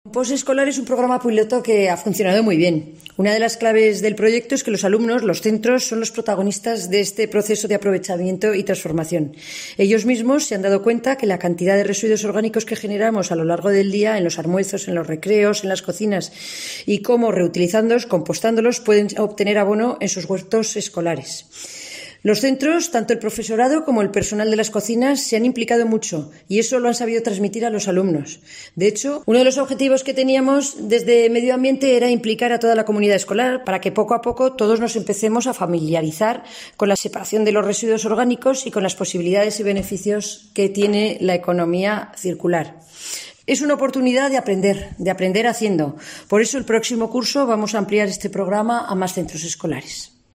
La consejera de Medio Ambiente Patricia Cavero, explica los detalles del proyecto piloto de compostaje escolar